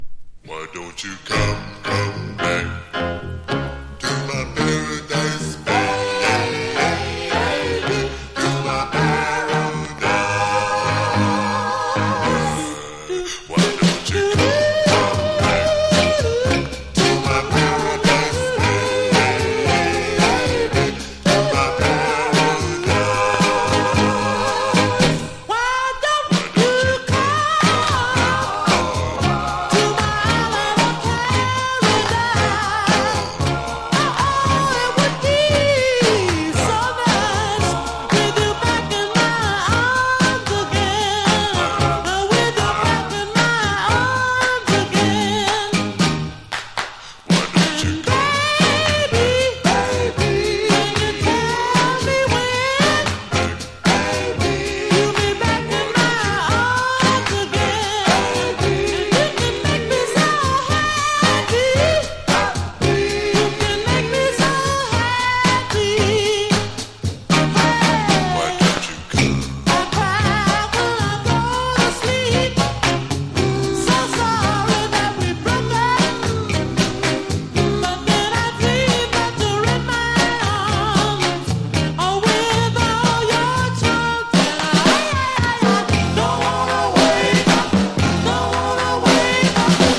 FUNK / DEEP FUNK# SOUL 45# 甘茶ソウル